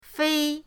fei1.mp3